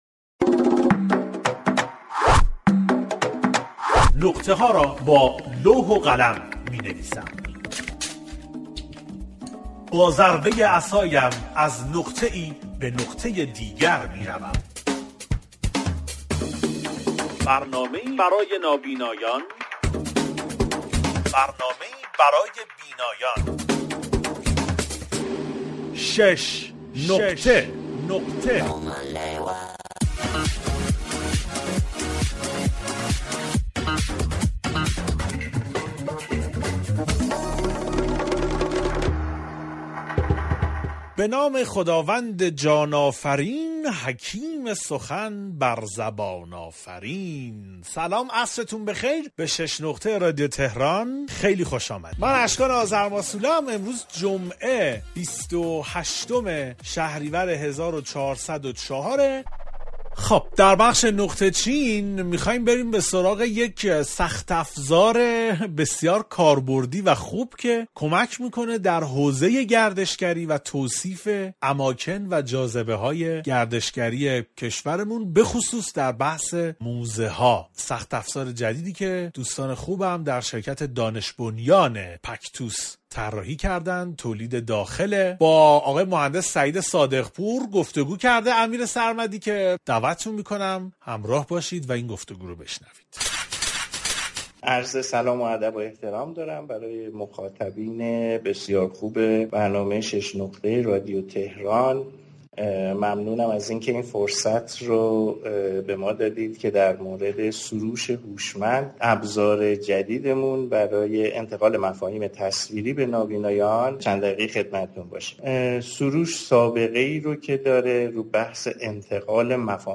دانلود فایل مصاحبه